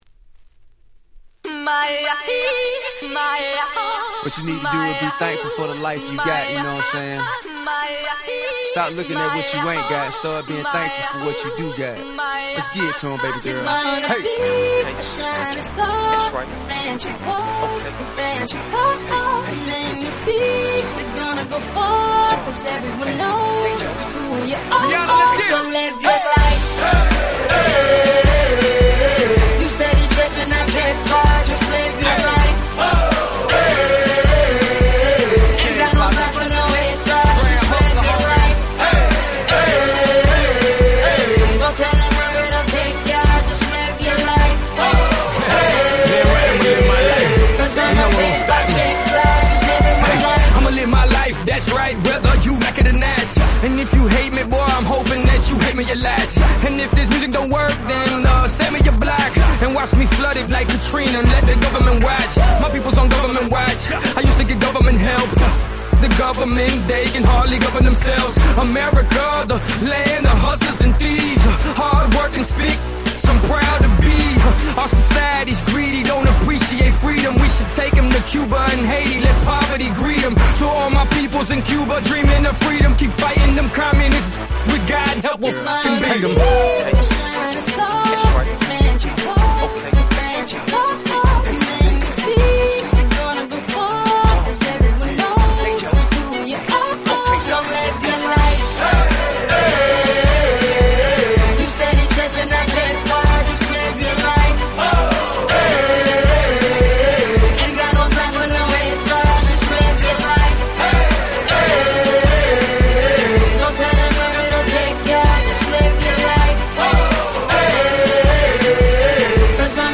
08' Nice Southern Hip Hop !!